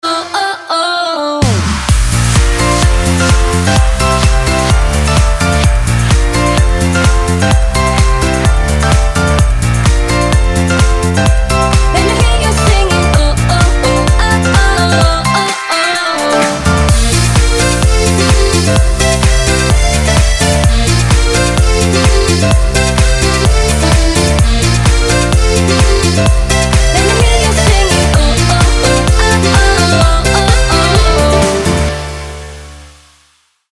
dance
Club House